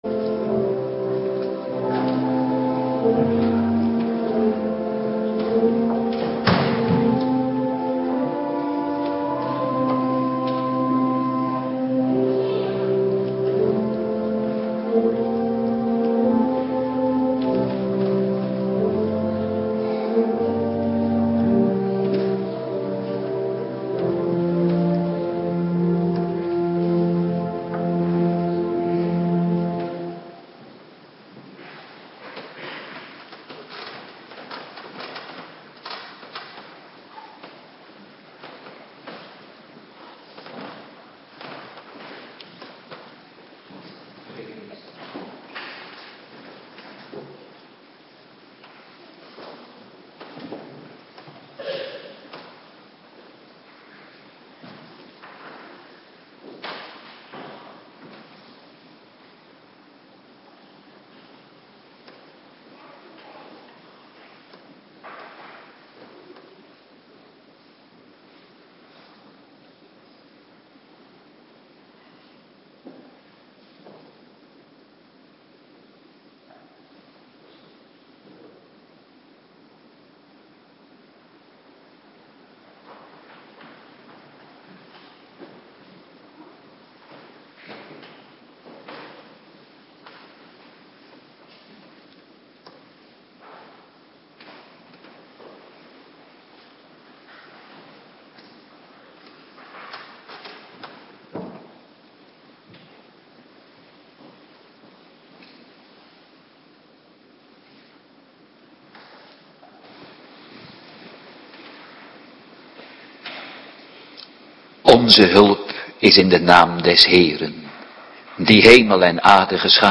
Terug Bekijk in volledig scherm Download PDF Morgendienst Voorbereiding Heilig Avondmaal
09:30 t/m 11:00 Locatie: Hervormde Gemeente Waarder Agenda